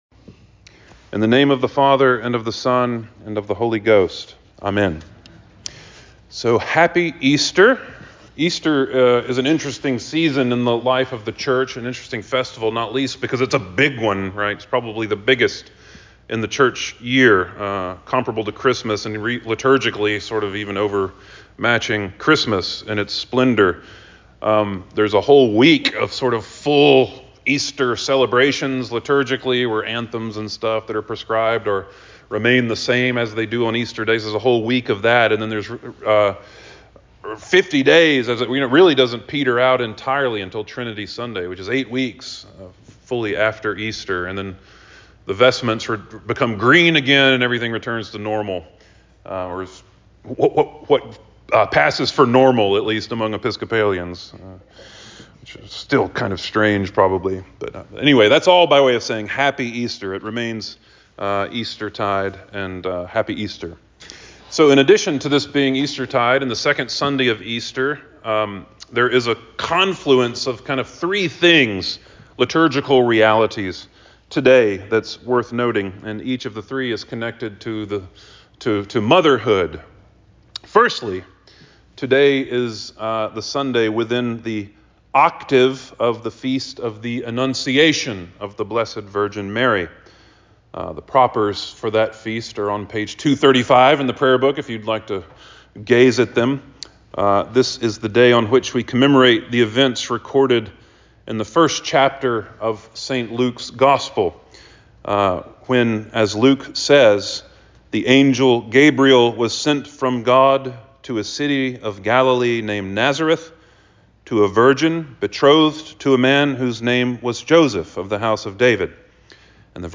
Sermon for the Second Sunday after Easter